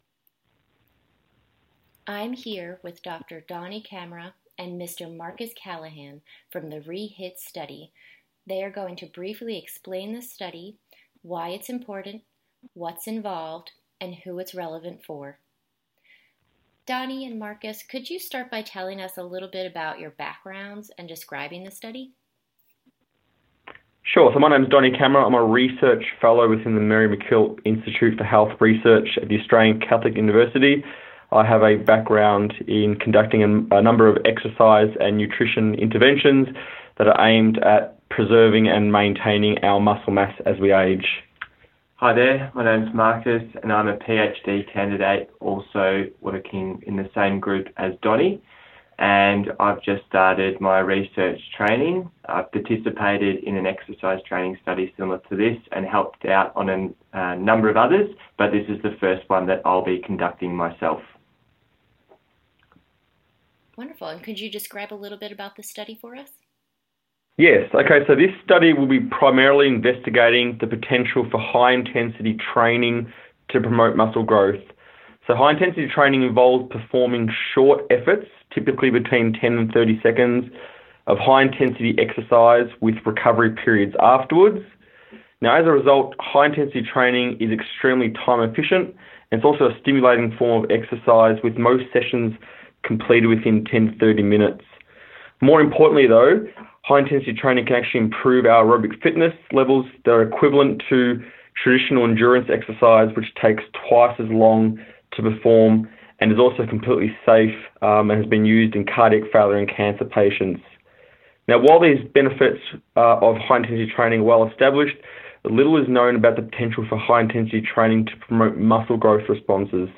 Researcher interview: